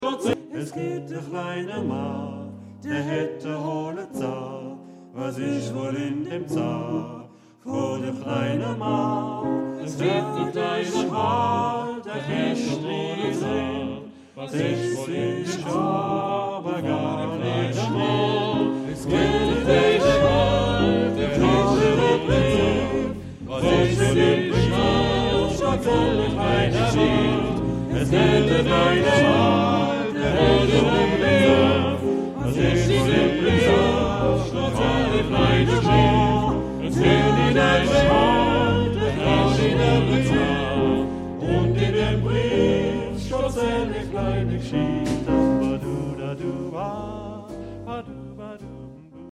Genre-Style-Form: Canon ; Jazz ; Secular
Type of Choir:  (3 equal voices )
Tonality: A major